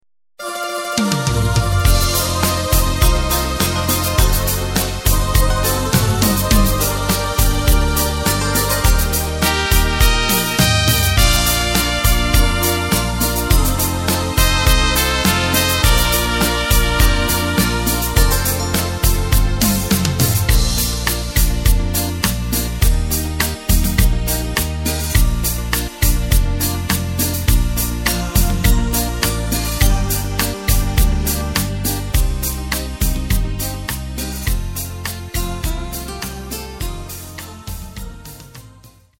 Tempo:         103.00
Tonart:            Bb
Niederländischer Schlager aus dem Jahr 2008!
Playback mp3 Demo